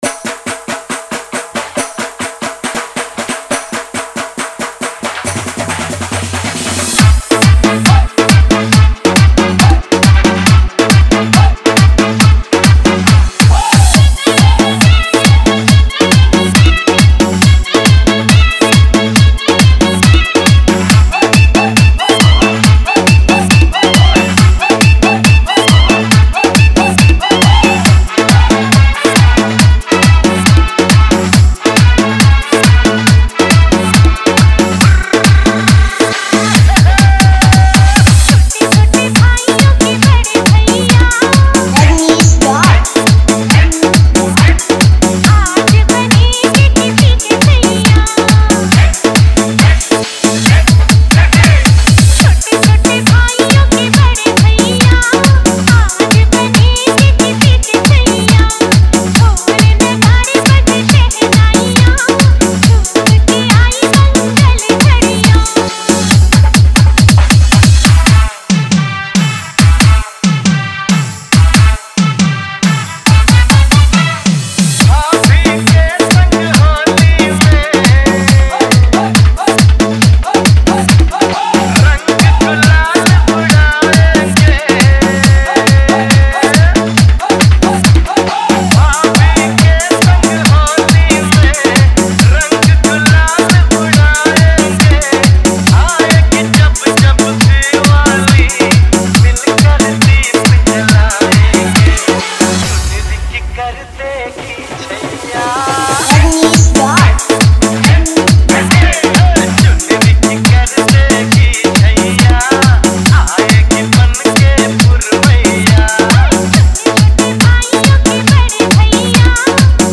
Wedding Dj Song